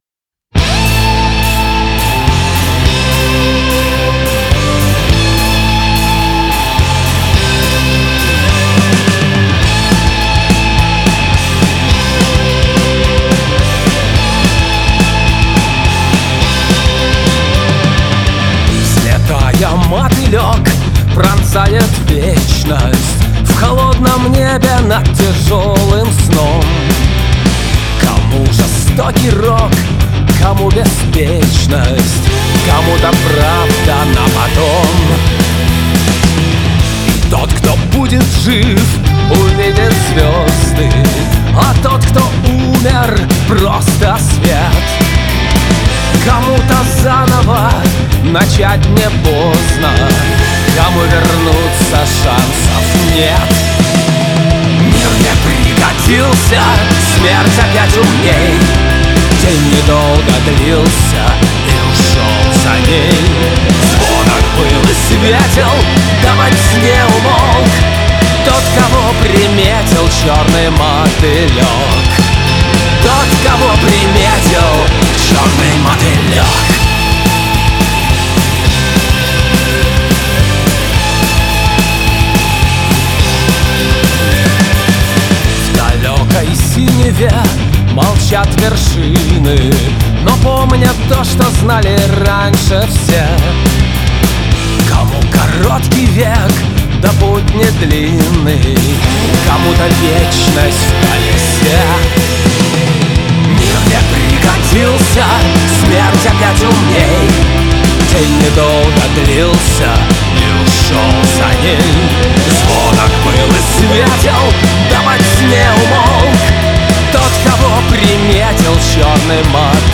• Жанр: Рок, Русская музыка, Русский рок